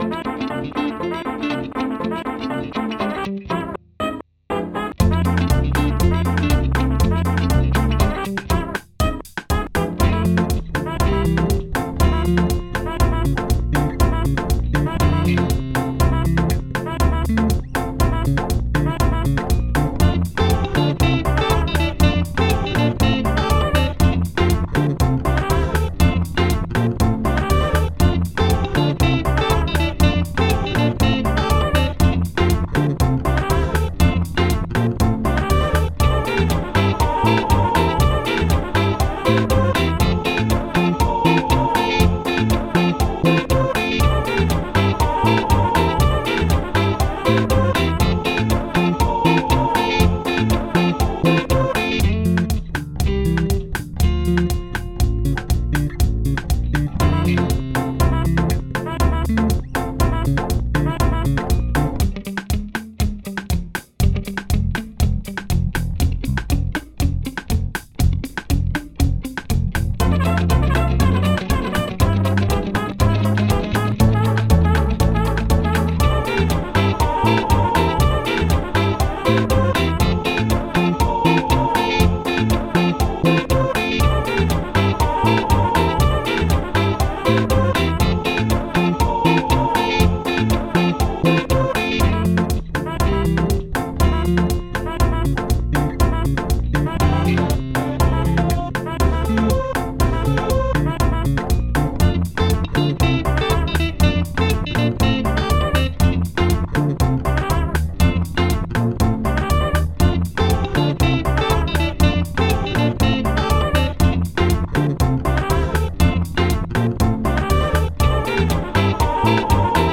A choir singing
was sampled and played over an acoustic song